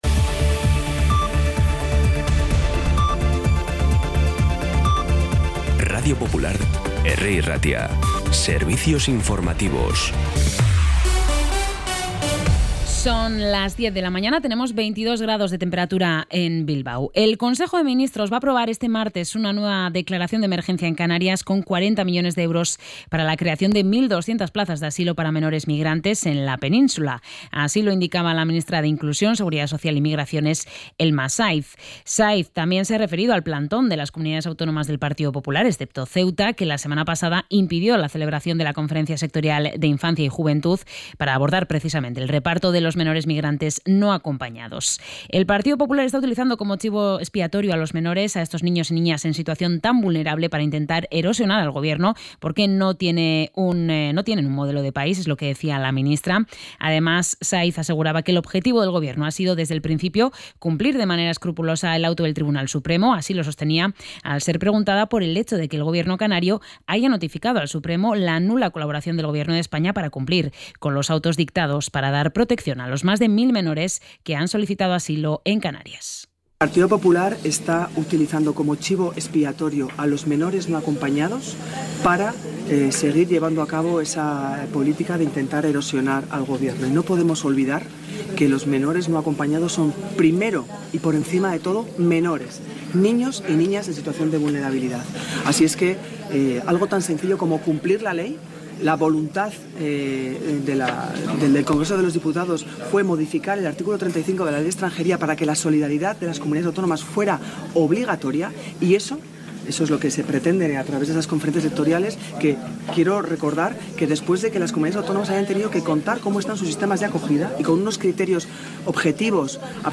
Información y actualidad desde las 10 h de la mañana